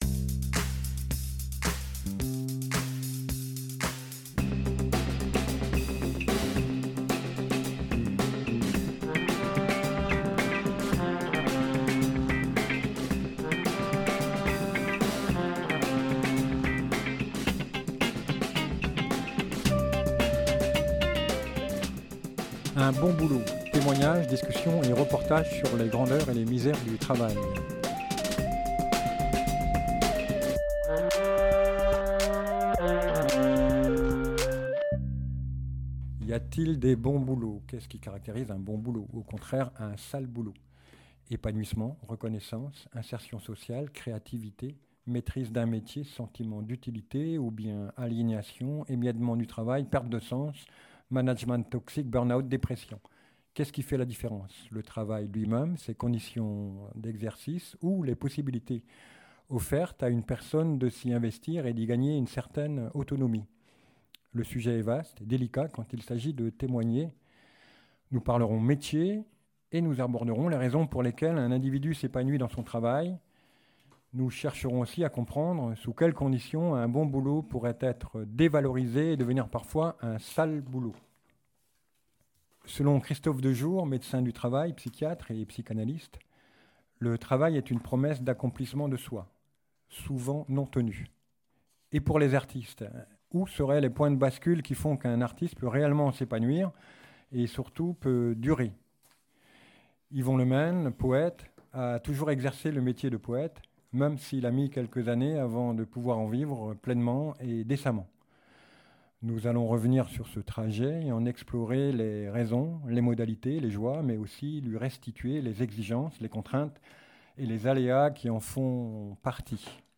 Il nous raconte dans ce premier entretien l’apprentissage de son métier de poète-interprète et les raisons très fortes pour lesquelles il a tenu le cap malgré la précarité inhérente à ce genre de profession.